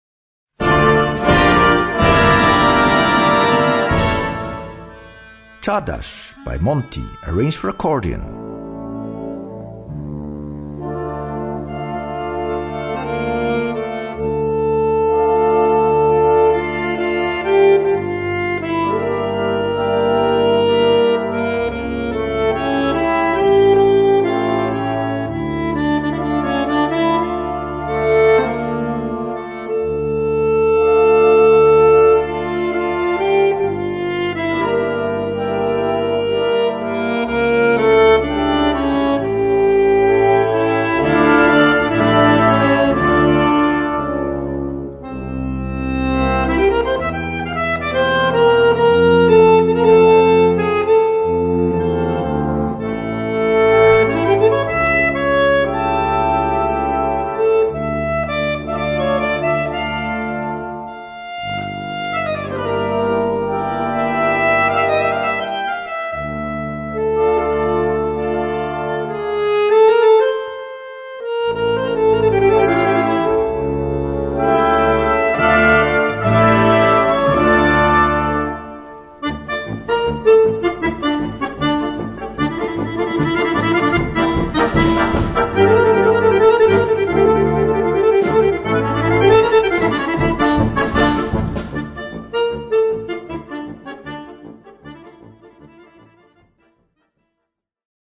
Noten für Blasorchester, oder Brass Band.